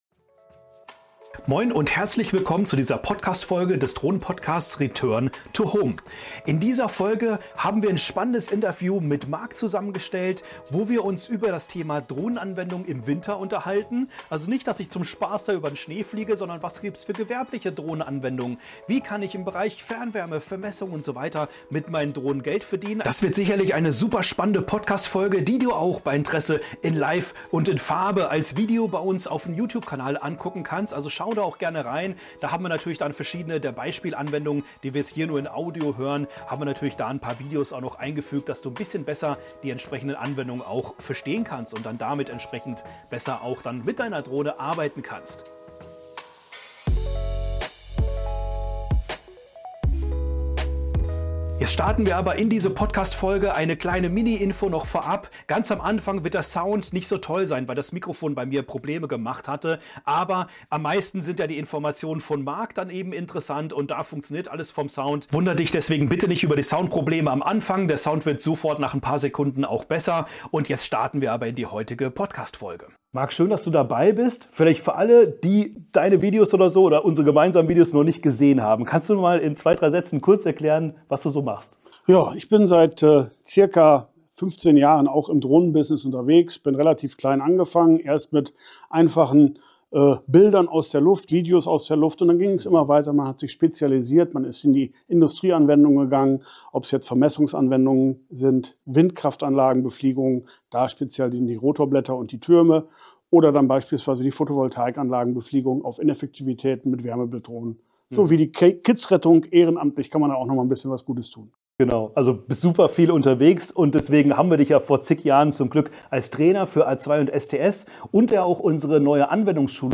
In diesem Interview